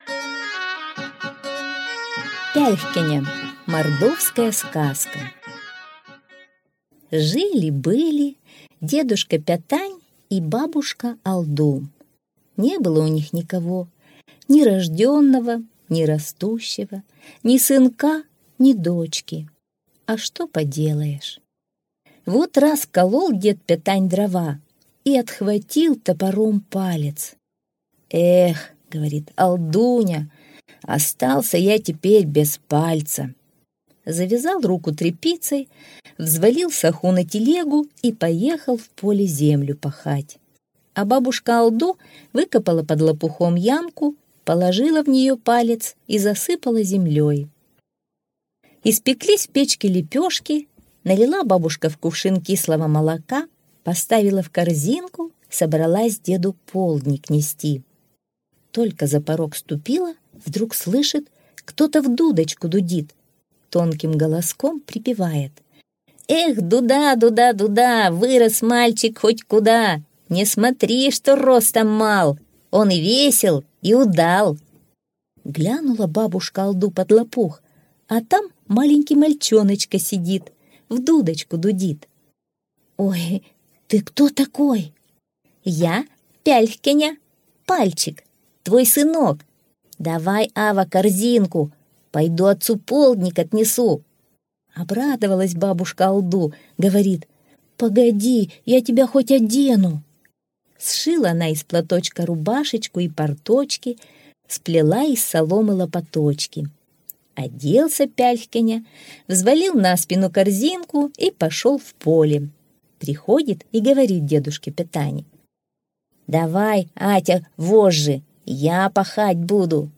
Аудиосказка «Пяльхкяня»